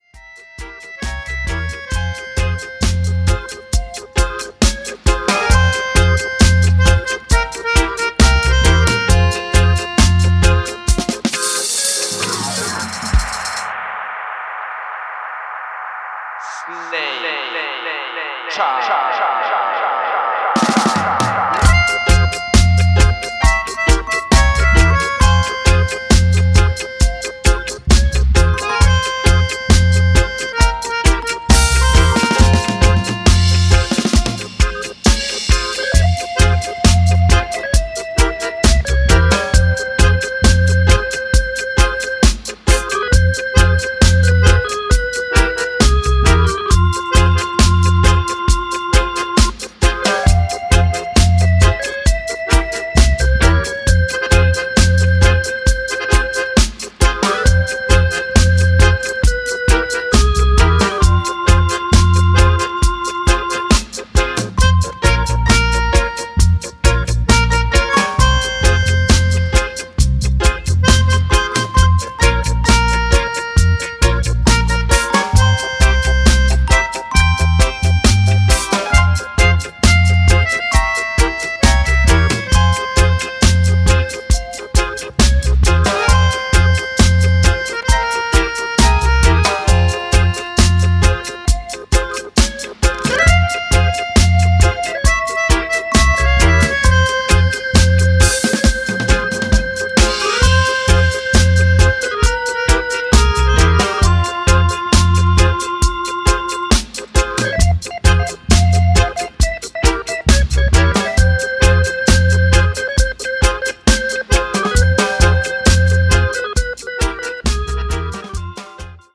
Reggae/Dub